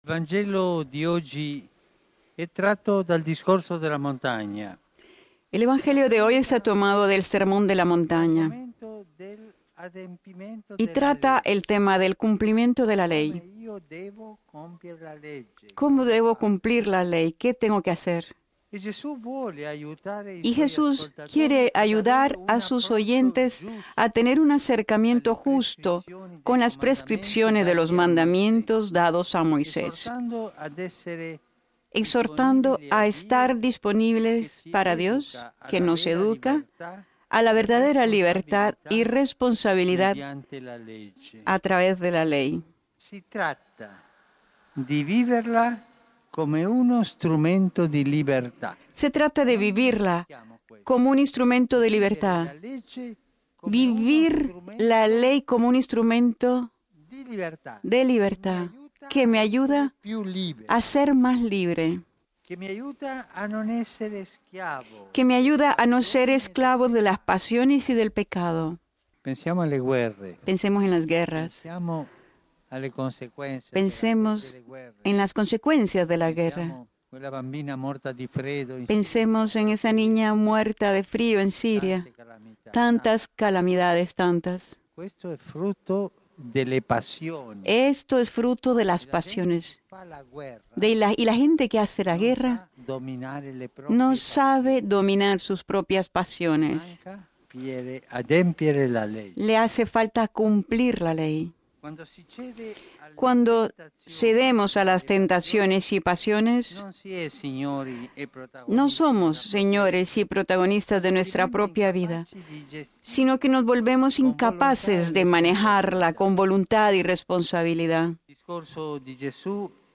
Escuche la reflexión del Papa a la hora del Ángelus: